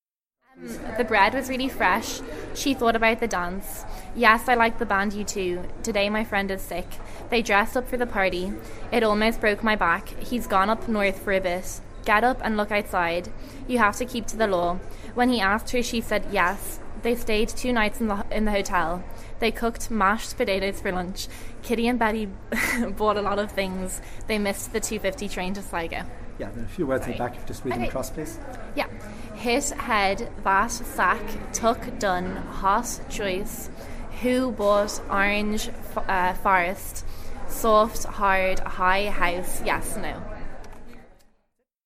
The following recording of a 20-year female illustrates the phenomenon clearly.
Advanced Short Front Vowel Lowering   (text read by speaker)
Note: the DRESS vowel (as in yes) is greatly lowered; the TRAP vowel (as in back) is lowered and retracted; the KIT vowel (as in sick) shows no lowering.
Sentences and words read by speaker in sound file
Dublin_Blackrock_(female).mp3